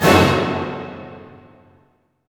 Index of /90_sSampleCDs/Roland - String Master Series/HIT_Dynamic Orch/HIT_Orch Hit dim
HIT ORCHD09R.wav